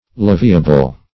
Search Result for " leviable" : The Collaborative International Dictionary of English v.0.48: Leviable \Lev"i*a*ble\ (l[e^]v"[i^]*[.a]*b'l), a. [From Levy to assess.]